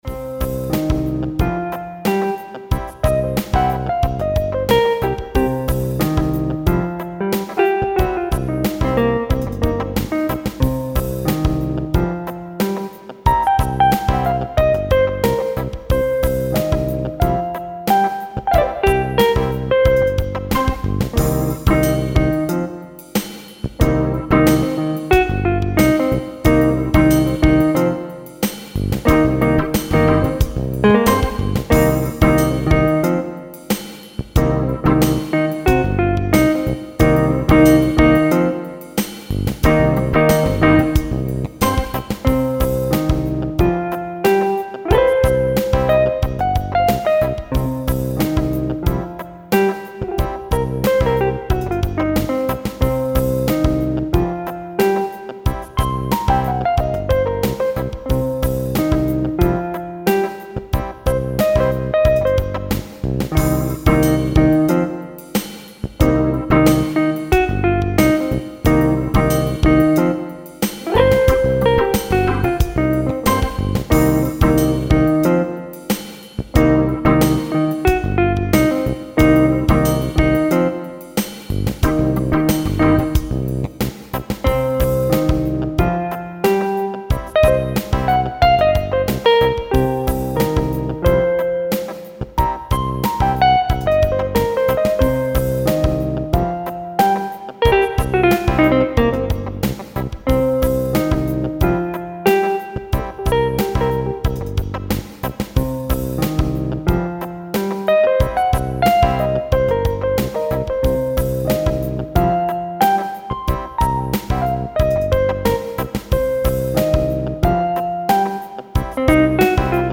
Tempo: 47 bpm / Date: 31.05.2017
Jazz /Creative Commons License 4.0 / noncommercial use free